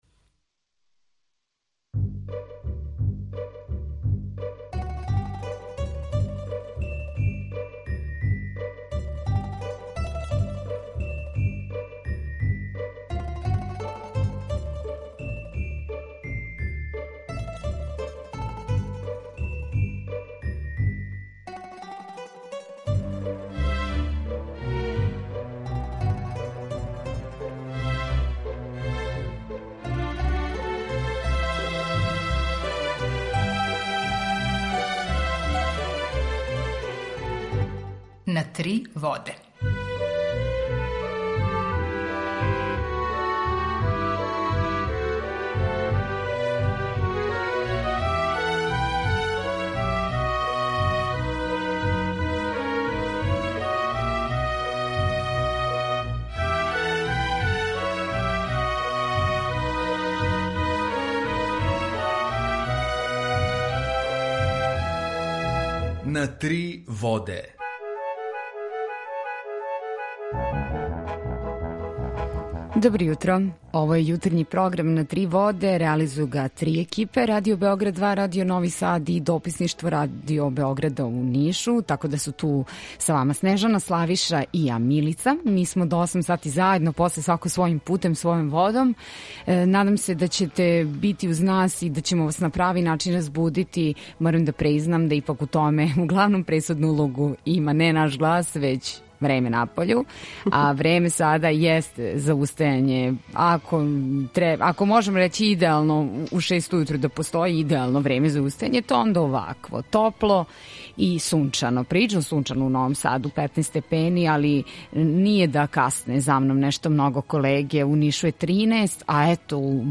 Укључење Косовске Митровице
Јутарњи програм из три студија
У два сата, ту је и добра музика, другачија у односу на остале радио-станице.